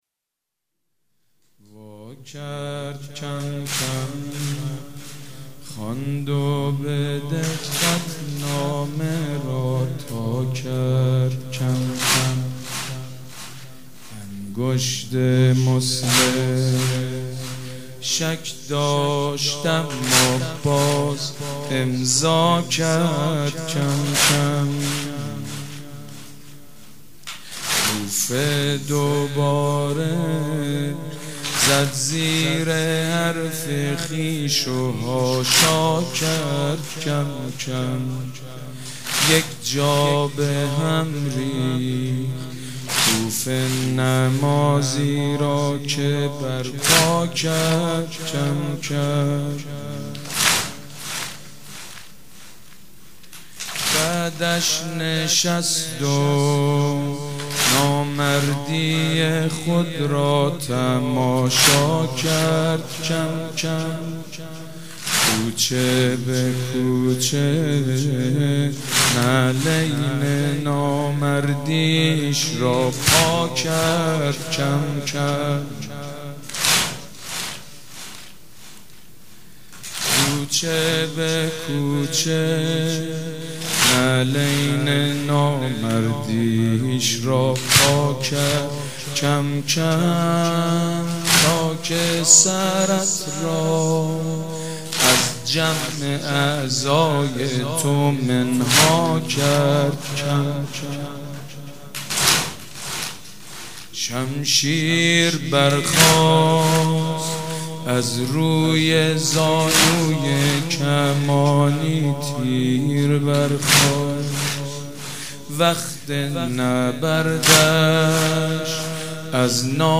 مناسبت : شب اول محرم
مداح : سیدمجید بنی‌فاطمه